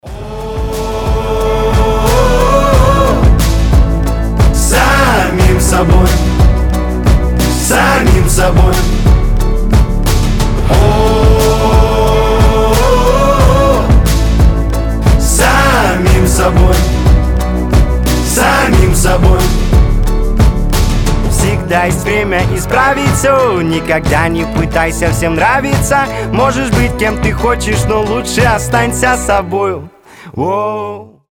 • Качество: 320, Stereo
вдохновляющие
alternative